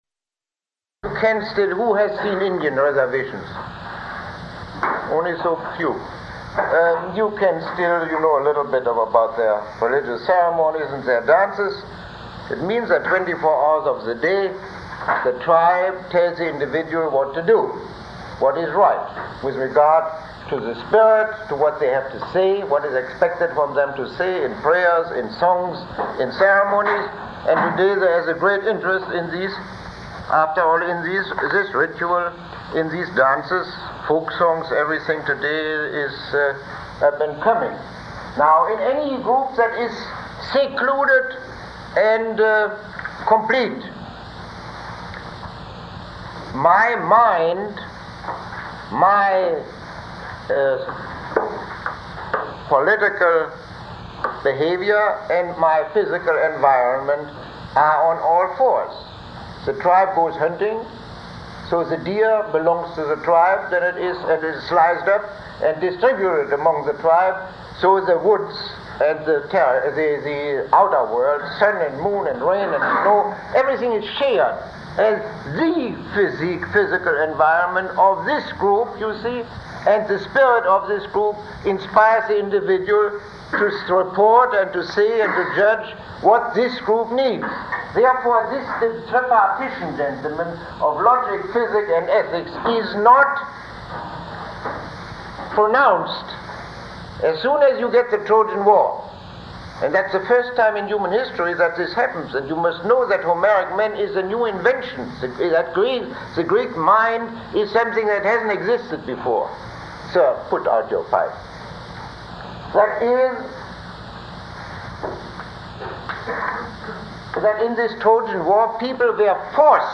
Lecture 15